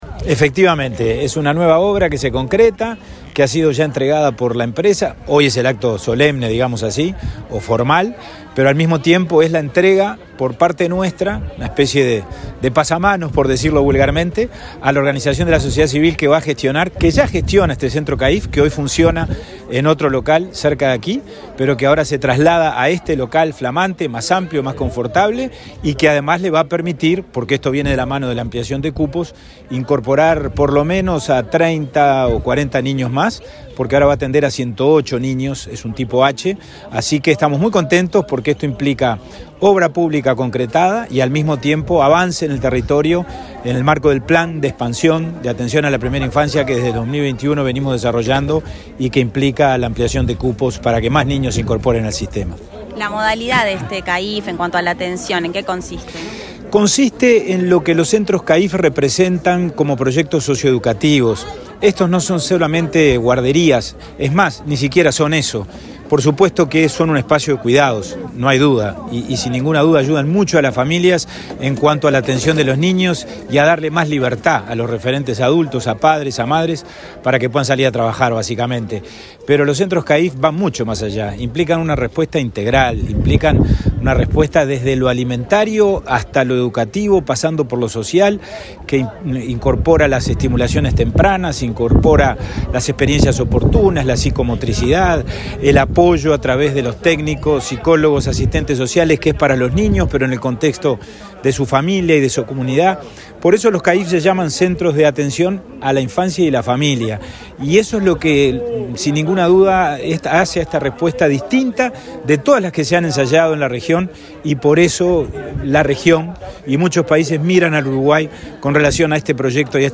Entrevista al presidente del INAU, Pablo Abdala
El presidente del Instituto del Niño y el Adolescente del Uruguay (INAU), Pablo Abdala, dialogó con Comunicación Presidencial en Canelones, antes de participar en el acto de entrega de la obra del centro de atención a la infancia y la familia (CAIF) Abuelo Ubaldo, en Shangrilá, y presentar a la organización civil Obra Social del Lago, que gestionará ese servicio.